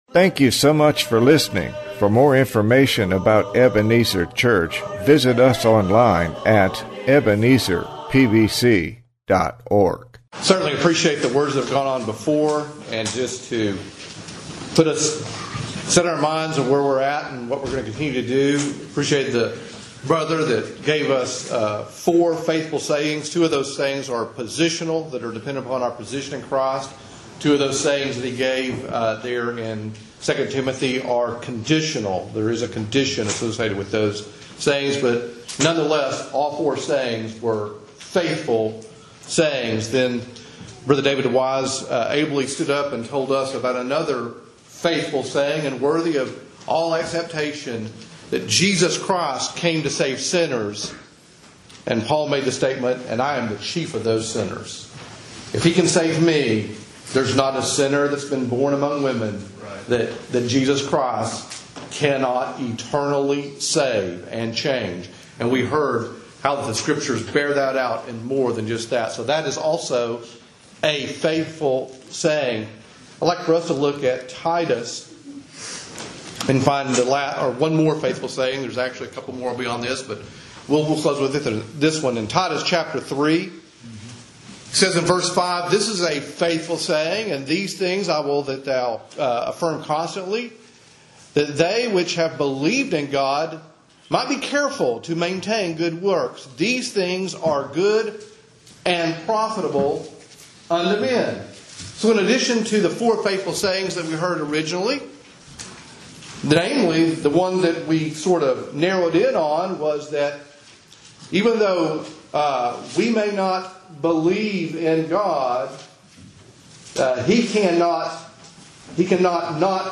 Preached Saturday morning of our 2019 Annual Meeting